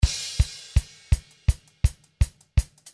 165 BPM - I Need Speed (68 variations)
There are 19 tom fills and there are some snare fills all so.
This loop song is chassidic beat style, fast and very energetic.